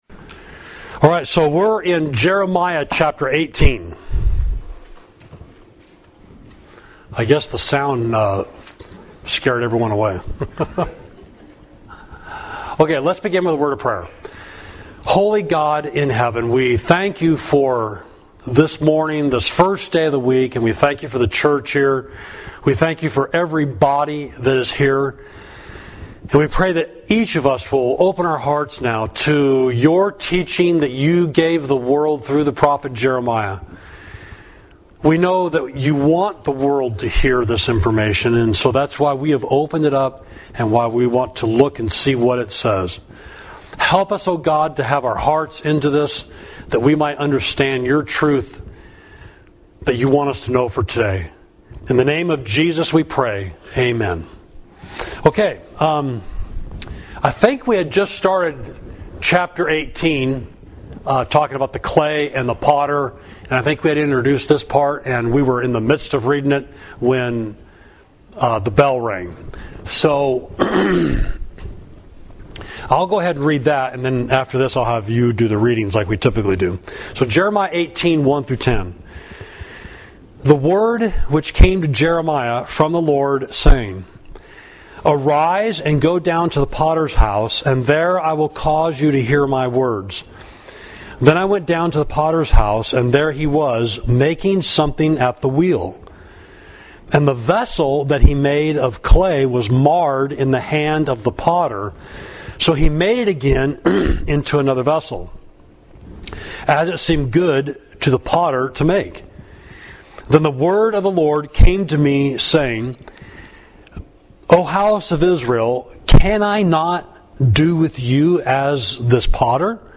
Class: Outline of Jeremiah
Adult Auditorium Class